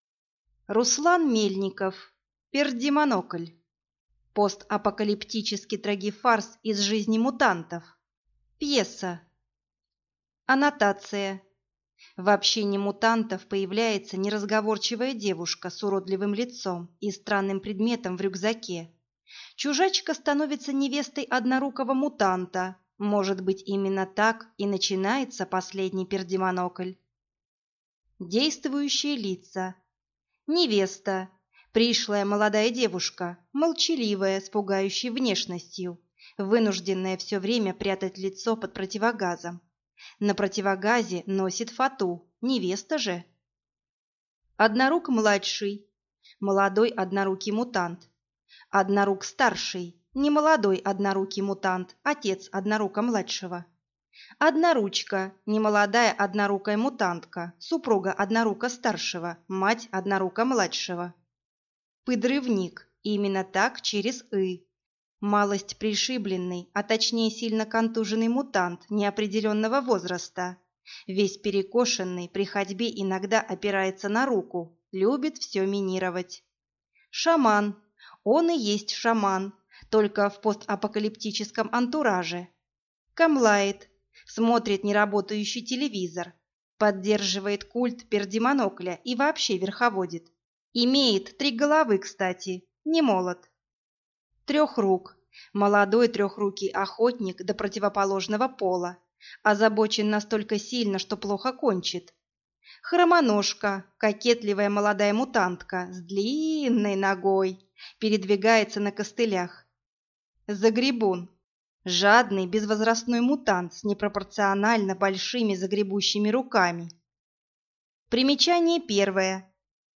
Аудиокнига Пердимонокль | Библиотека аудиокниг